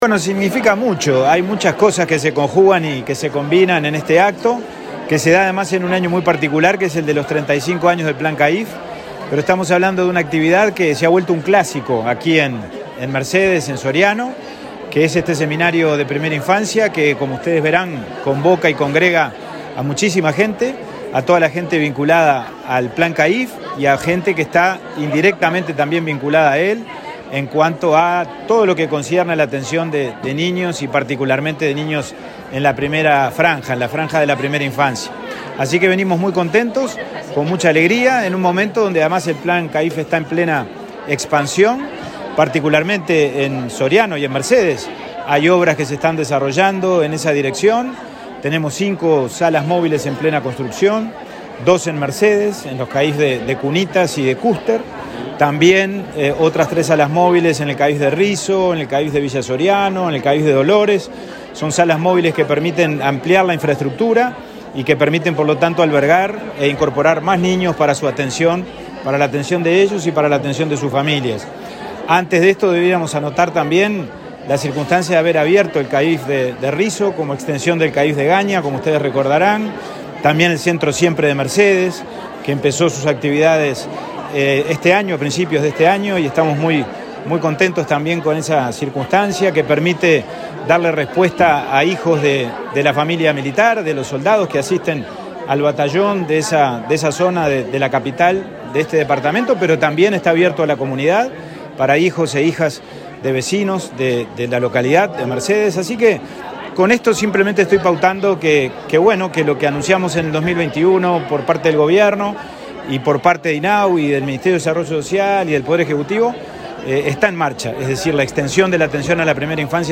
Declaraciones del presidente del INAU, Pablo Abdala, a la prensa
El presidente del Instituto del Niño y el Adolescente del Uruguay (INAU), Pablo Abdala, dialogó con la prensa en Soriano, antes de participar en el 9.